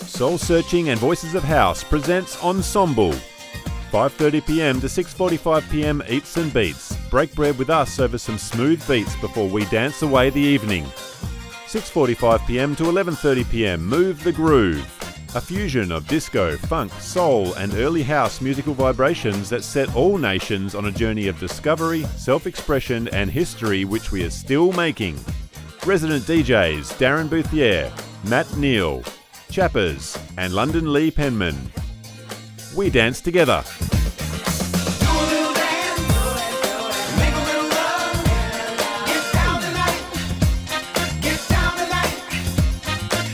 Male
English (Australian)
Deep, comforting, trustworthy, steady voice.
Radio Commercials
Event Advertisement
Words that describe my voice are Trustworthy, Calm, Deep.